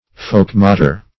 Search Result for " folkmoter" : The Collaborative International Dictionary of English v.0.48: Folkmoter \Folk"mot`er\, n. One who takes part in a folkmote, or local court.